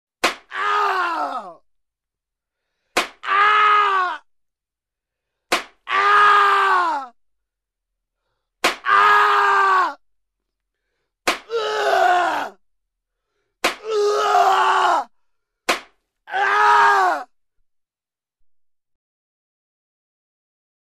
SFX鞭打男人时发出的鞭打声和惨叫声音效下载
SFX音效